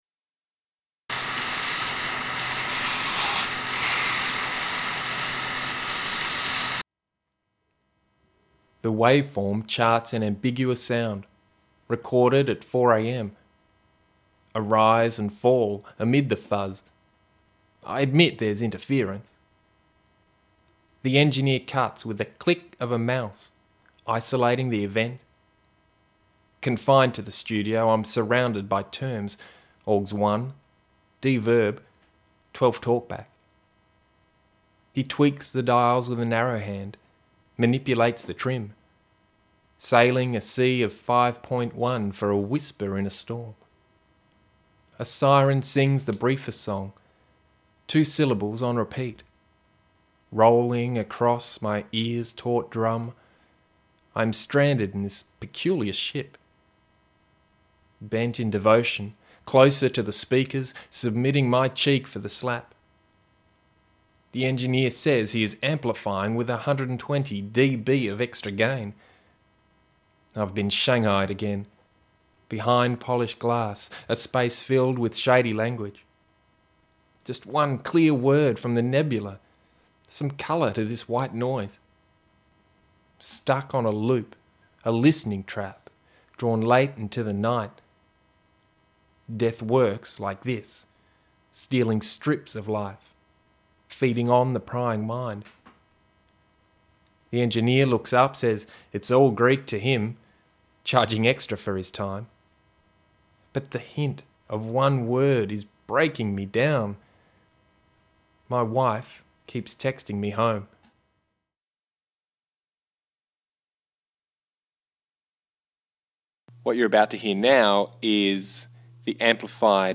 (audio includes the “ambiguous sound” heard in the hearse)